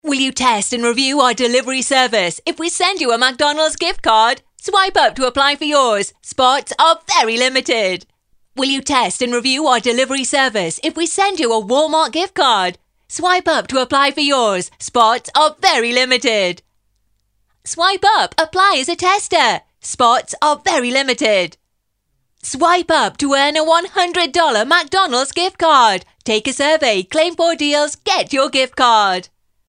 女英115 英式英语 多变全能 激情激昂|科技感|积极向上|时尚活力|神秘性感|亲切甜美|素人